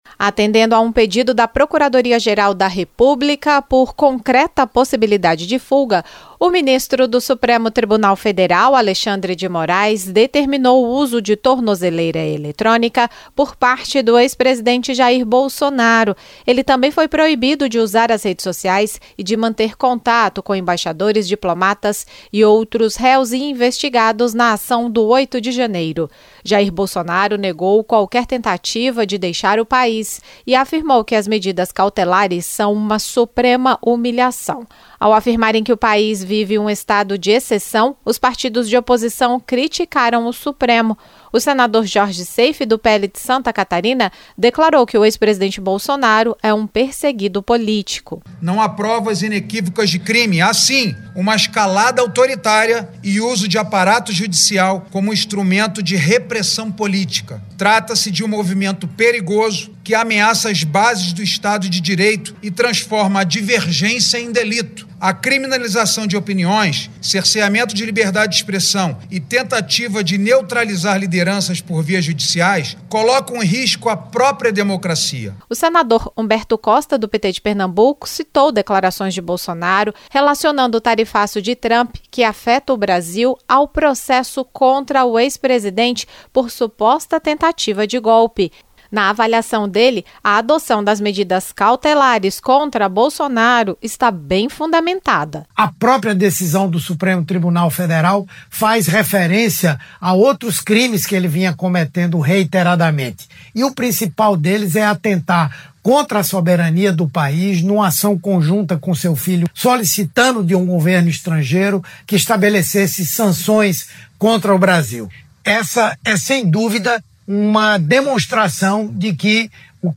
O senador Jorge Seif (PL-SC) disse que o ex-presidente não foi condenado e se tornou alvo de "repressão do aparato judicial". Já o senador Humberto Costa (PT-PE) avalia que as declarações de Bolsonaro vinculando o tarifaço de Trump contra o Brasil ao julgamento dele numa tentativa de ingerência no STF podem reforçar o pedido de prisão.